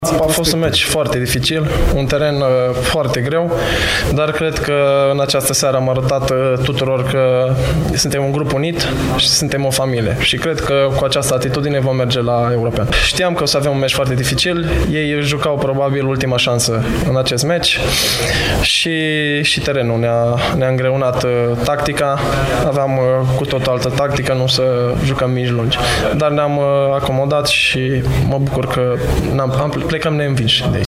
Despre meciul de la Prishtina a vorbit și portarul României, Horațiu Moldovan:
AR-Horatiu-Moldovan.mp3